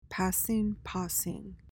PRONUNCIATION:
(PAS-ing, PAH-sing)